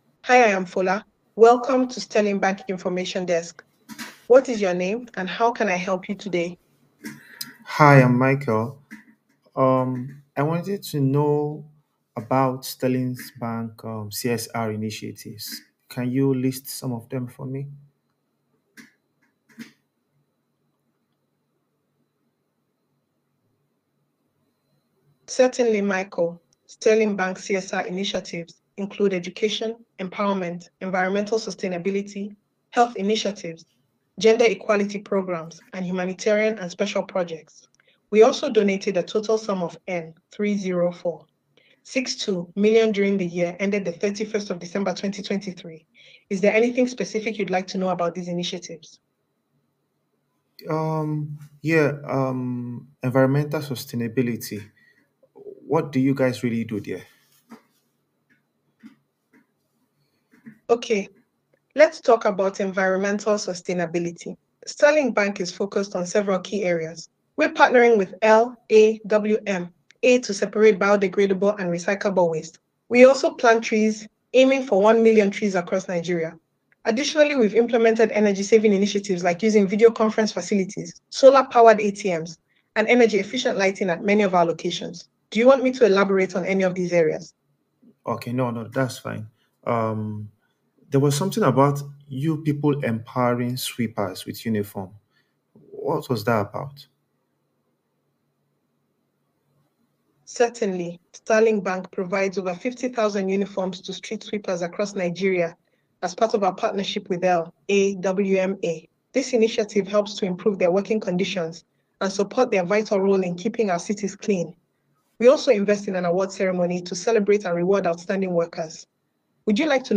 subscription-based army of call center voice agents
with 80+ African voices.
Sterling-Bank-CSR-voicebot_convo-1.mp3